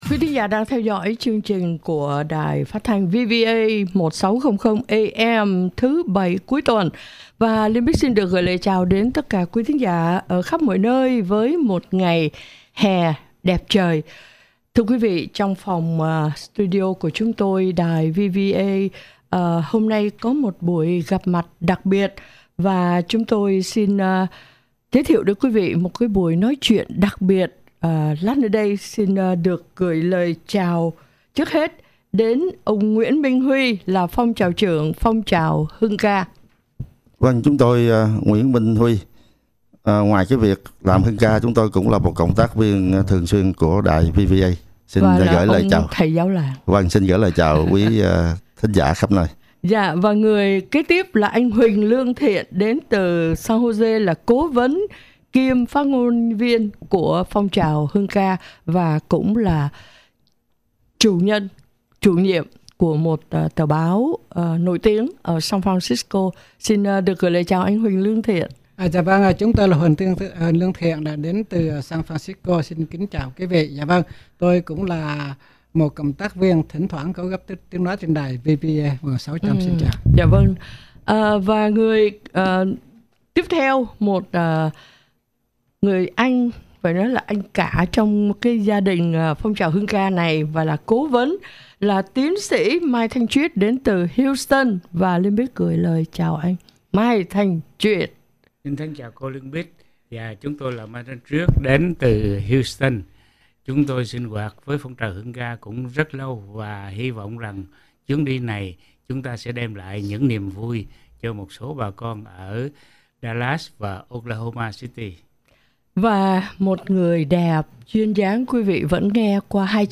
VVA1600 AM-Hội Thoại Với Thành Viên Phong Trào Hưng Ca